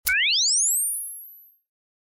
Звуки прибора ночного видения